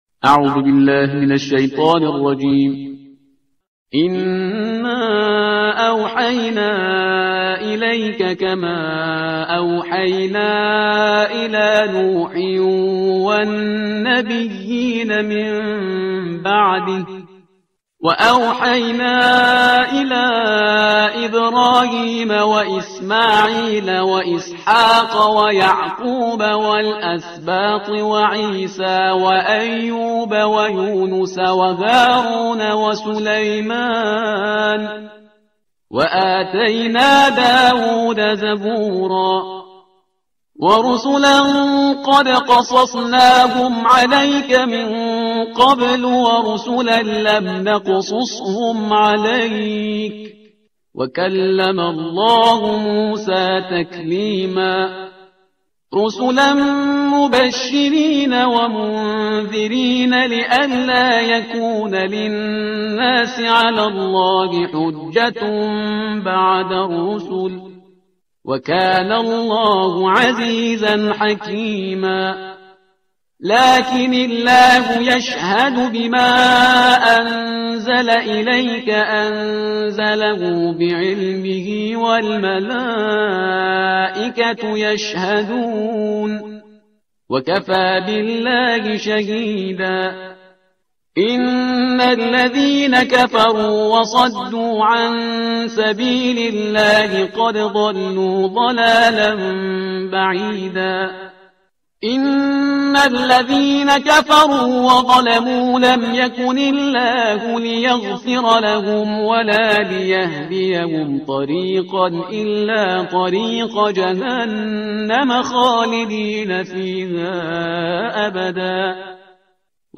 ترتیل صفحه 104 قرآن با صدای شهریار پرهیزگار